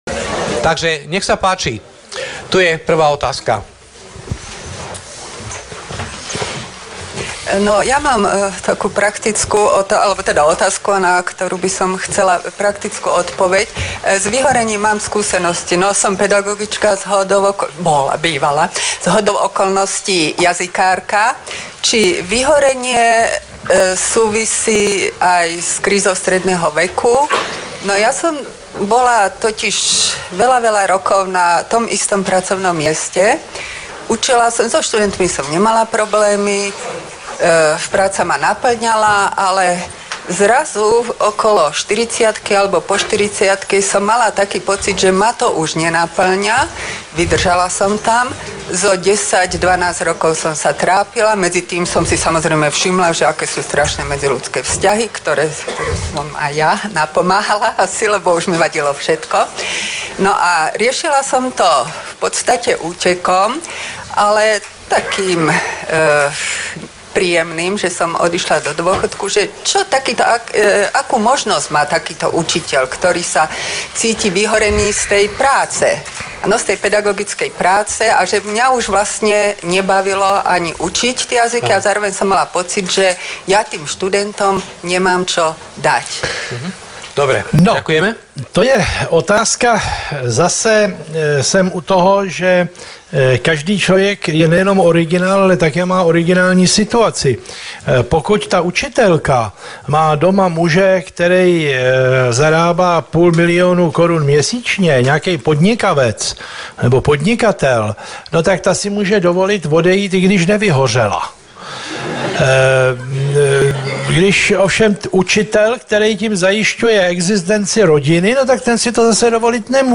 Diskusia: O syndróme vyhorenia (2), 6.9.2012
Diskusia.O.syndrome.vyhorenia.2.mp3